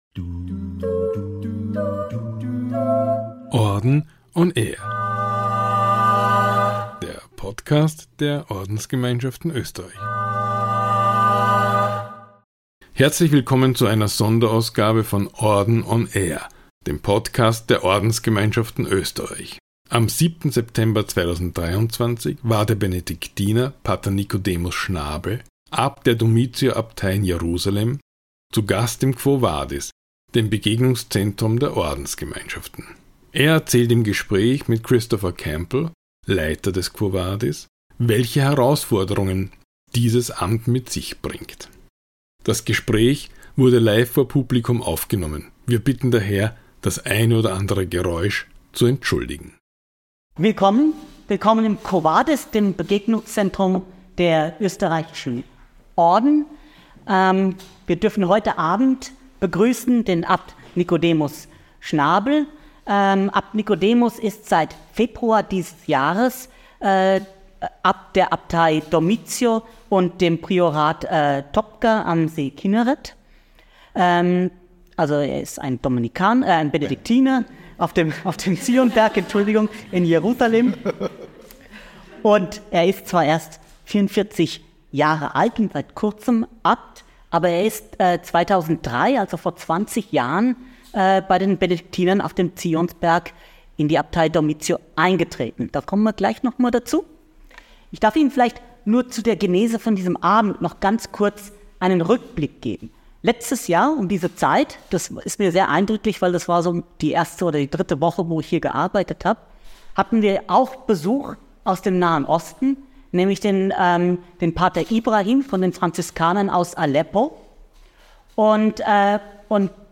In Folge #26 von „Orden on air“ ist P. Nikodemus Schnabel, Abt der Jerusalemer Dormition Abtei, zu Gast im Quo vadis, dem Begegnungszentrum der Ordensgemeinschaften Österreich, in Wien.